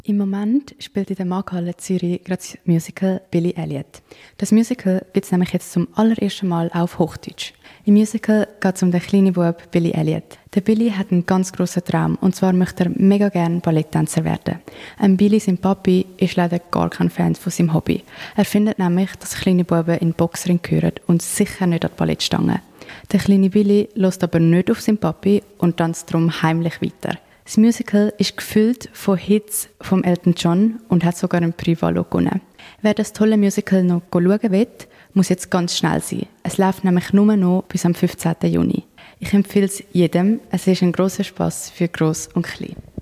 Zurzeit läuft das Erfolgsmusical Billy Elliot. Um was es in diesem Musical geht und welche Abendteuer der kleine Billy erlebt, hört man im folgenden Radiobeitrag dazu.